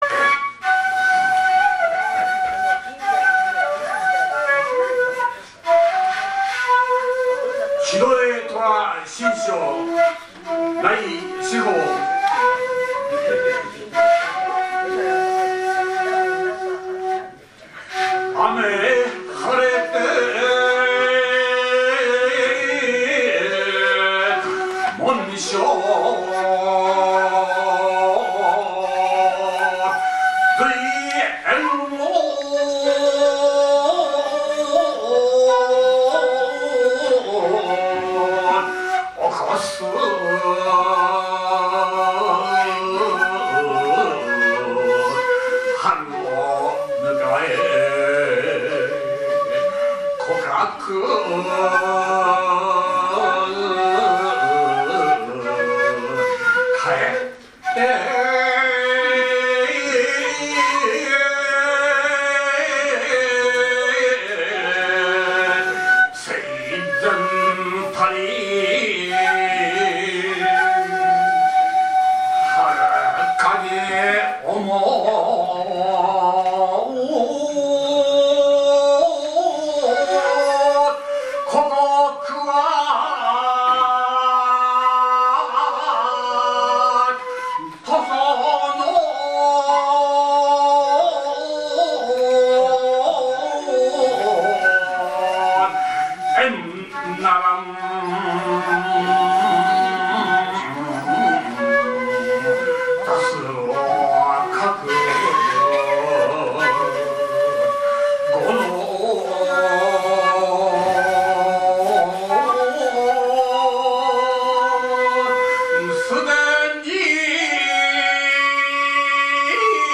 詩吟神風流 第９１回全国大会特集
平成２９年１１月１８日（土） 於 上野精養軒
会長・支部長吟詠